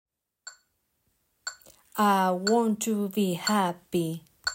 Rhythm and words
They contain phrases pronounced imitating the scores on the second column.